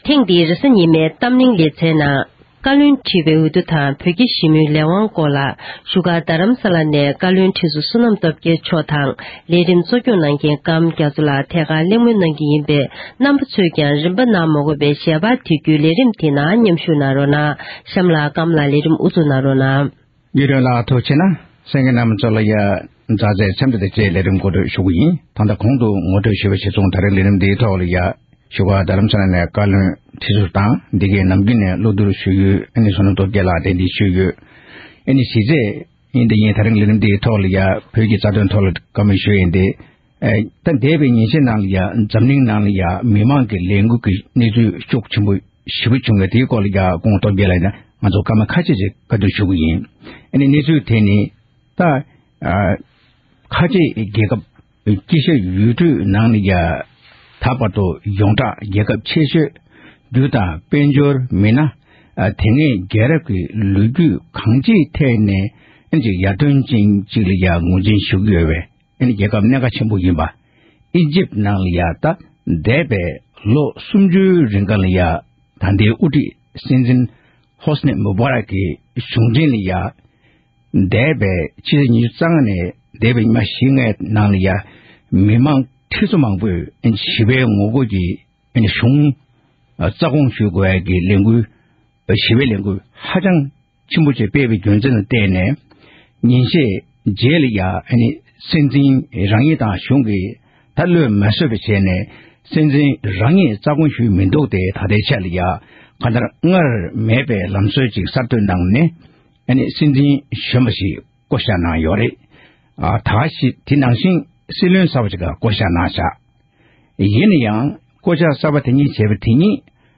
བཀའ་བློན་ཁྲི་པའི་འོས་བསྡུ་དང་བོད་རྒྱ་ཞི་མོལ་ཐོག་བགྲོ་གླེང་གནང་བ།